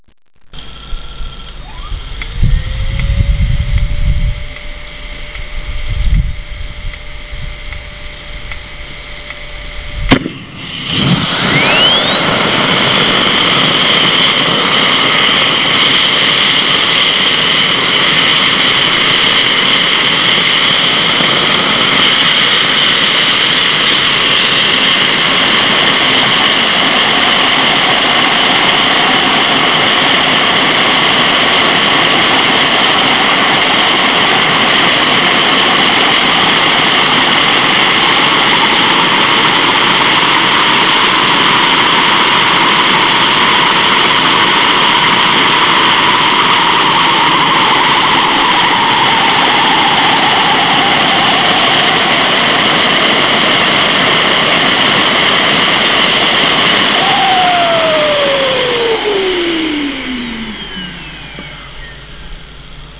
Click here to hear the start-up  Initially you can hear the oil pump, then the starter blower and the high-energy spark discharges. Ignition is rather explosive, and the turbine spins up incredibly quickly (due to its small size) to about 60,000rpm. It runs up to 100,000 rpm, back to 60,000rpm and finally the fuel is switched off.